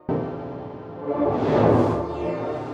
Having a wii on hand certainly makes up for the missing PenTilt (etc) on the Wacom bamboo - am really enjoying the new textures which a pen tablet allows!
wii-micro-pen-samplecloud.aif